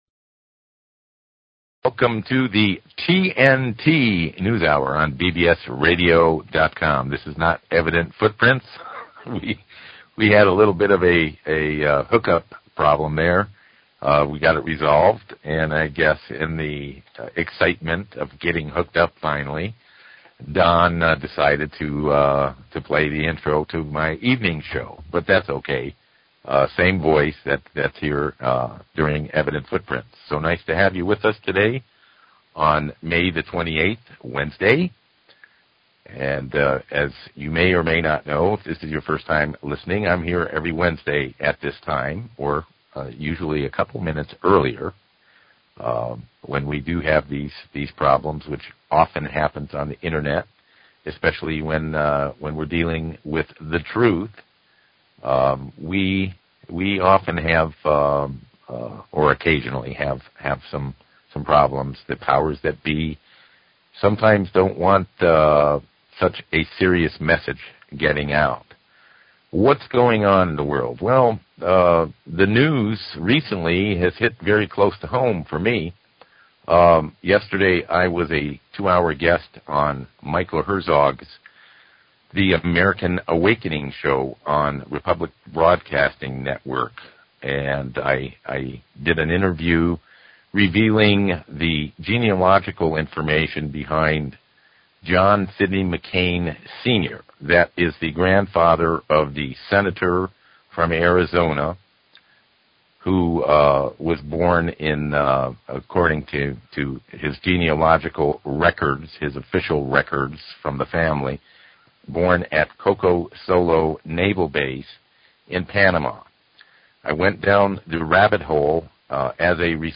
Talk Show Episode, Audio Podcast, The_TNT_Hour and Courtesy of BBS Radio on , show guests , about , categorized as